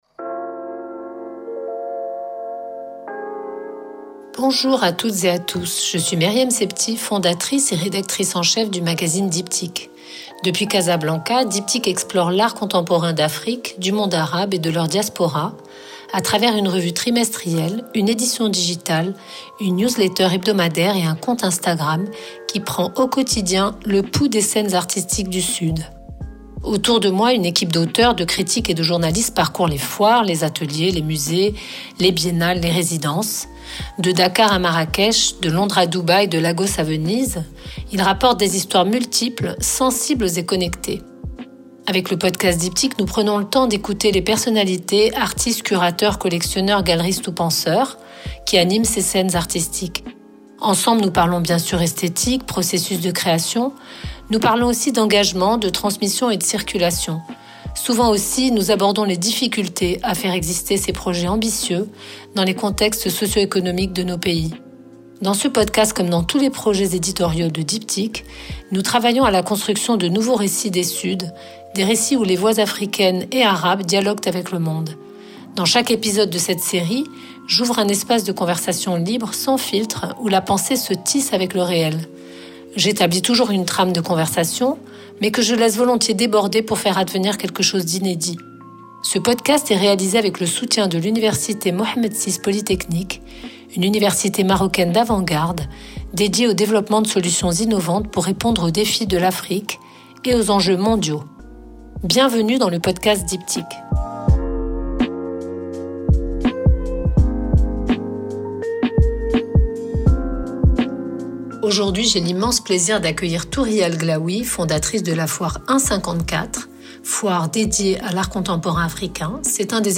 Enregistré à Somerset House, au cœur de la foire 1-54 London 2025, cet épisode capte Touria El Glaoui dans son élément, en pleine orchestration de cette édition londonienne qui marque une étape stratégique dans l’histoire de la foire : le basculement assumé de l’art africain et diasporique du récit périphérique vers le canon institutionnel.